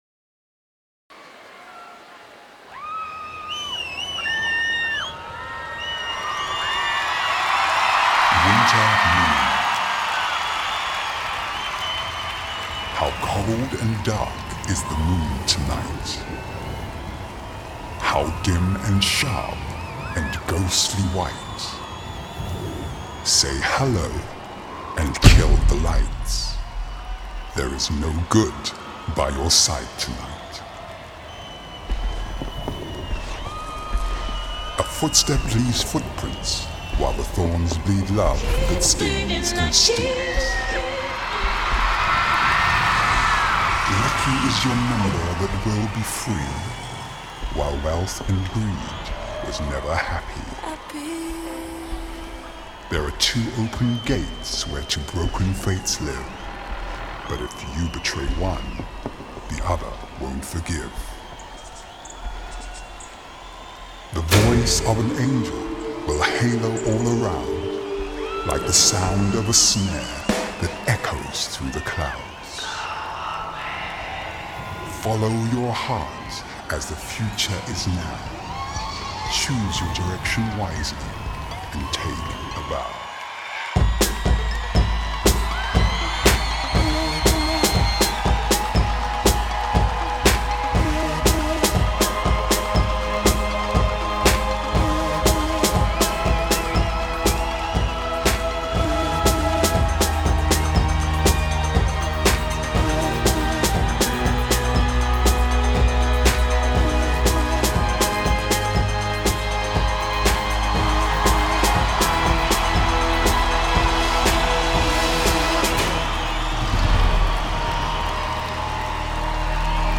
Synth-pop